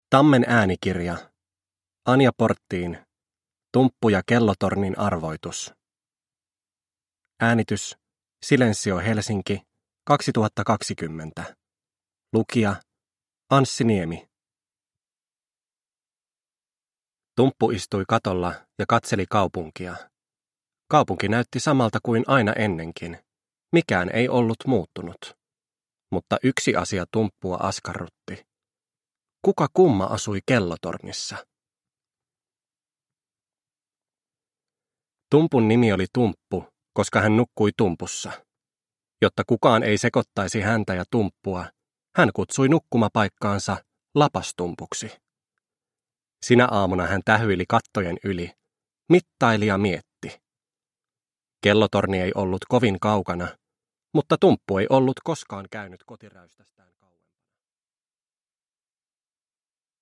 Tumppu ja kellotornin arvoitus – Ljudbok – Laddas ner